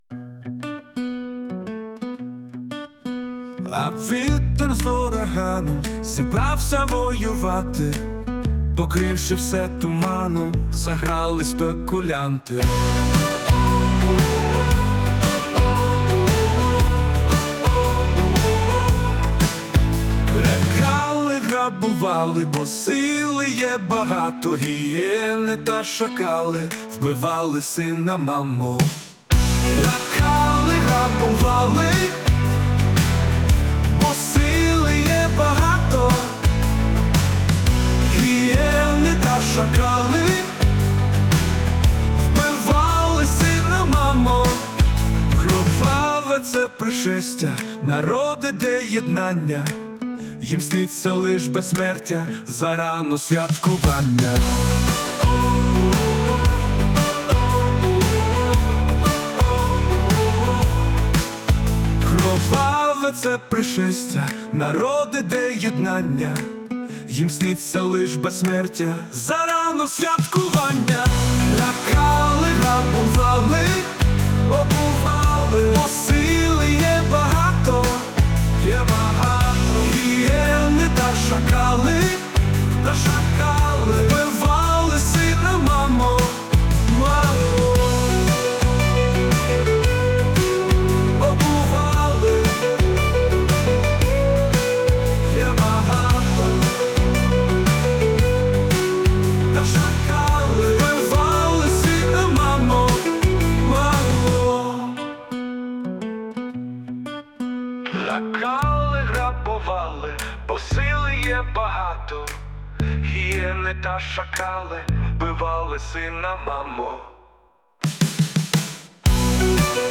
Музична композиція створена за допомогою ШІ
така досить жорстка у вас вийшла композиція ... така болюча реальність ...